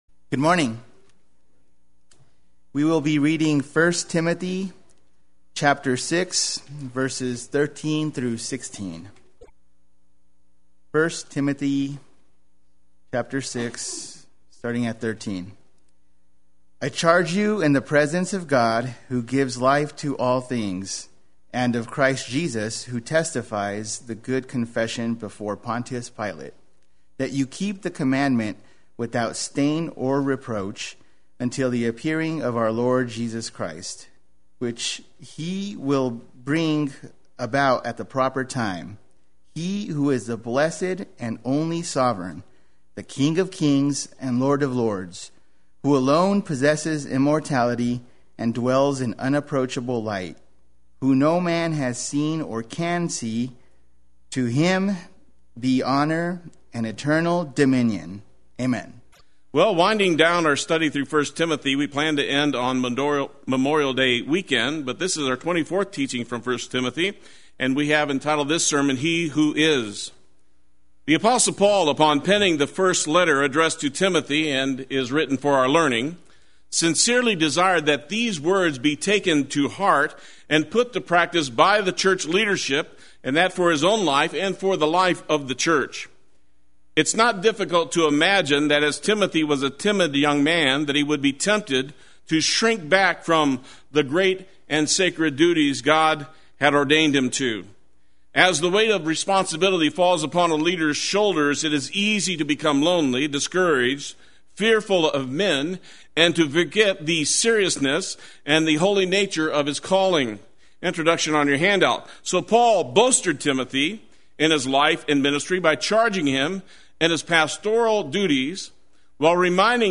Play Sermon Get HCF Teaching Automatically.
He Who Is Sunday Worship